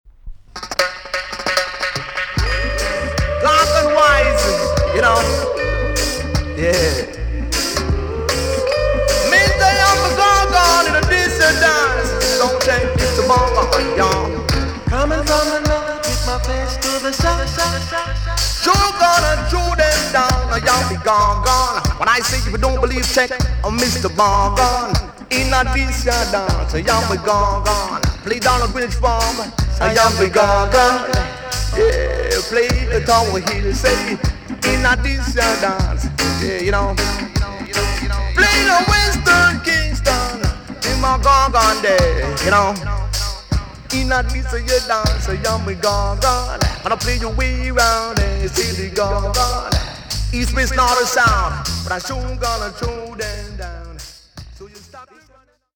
A.SIDE EX- 音はキレイです。